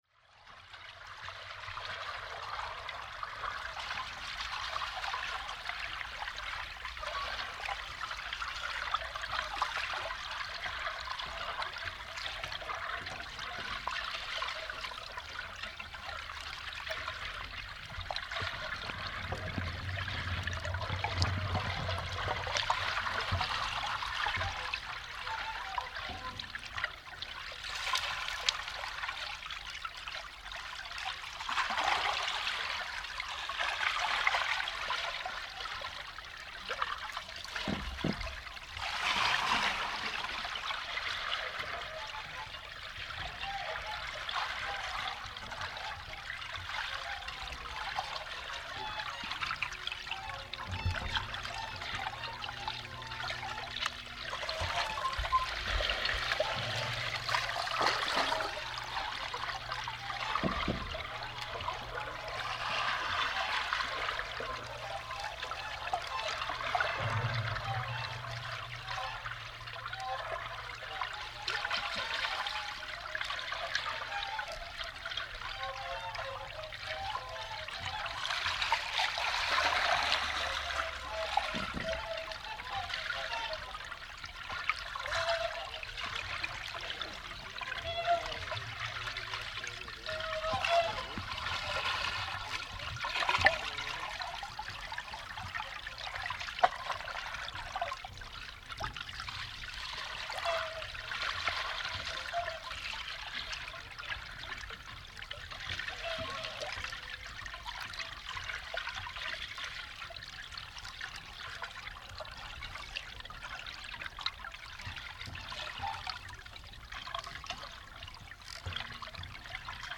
Undir yfirborði tjarnarinnar heyrast mikil skvamphljóð frá fuglum sem börðust um brauðið á yfirborðinu, einnig í skúfönd sem oftsinnis kafaði nærri hljóðnemunum. Þá heyrist málmhljóð þegar gengið er á brúnni og eitthvað slæst í burðarvirki hennar.
Two hydrophones where placed 2 meters apart and 20 cm above the pond bottom.
In the pond.
water.mp3